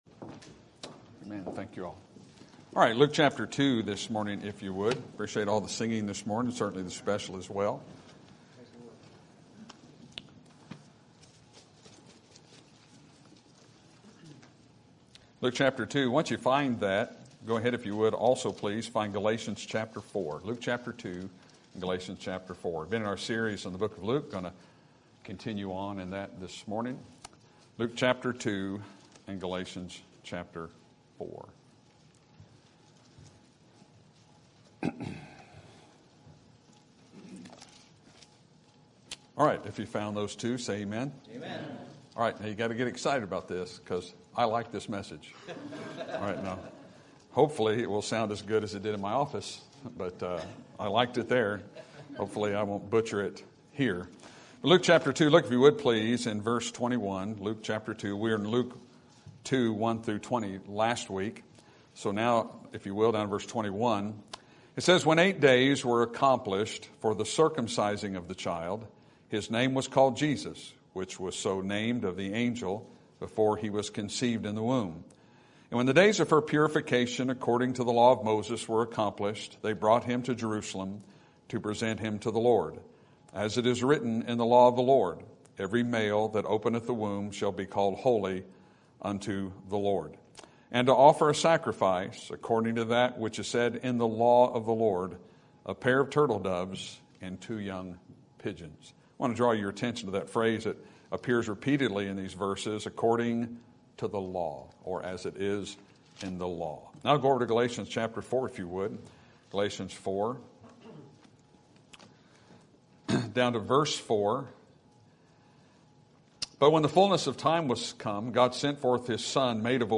Sermon Topic: Book of Luke Sermon Type: Series Sermon Audio: Sermon download: Download (23.9 MB) Sermon Tags: Luke Gospel Salvation Jesus